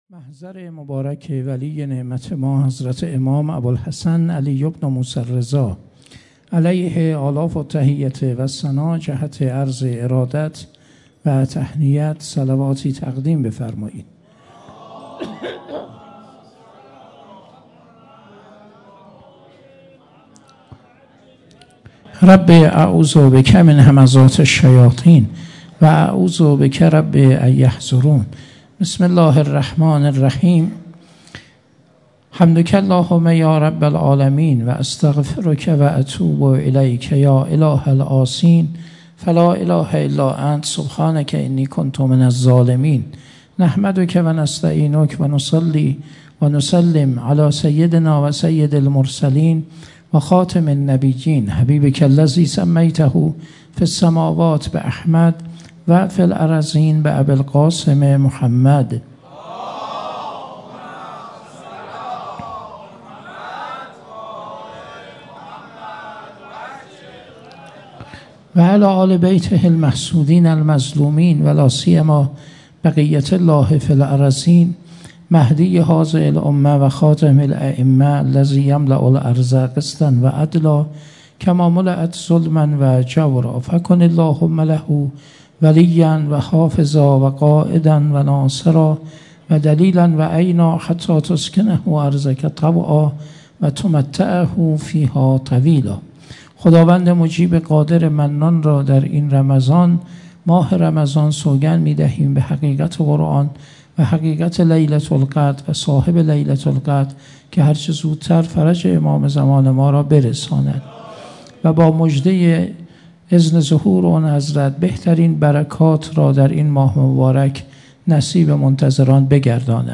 حسینیه انصارالحسین علیه السلام